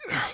B_pain6.ogg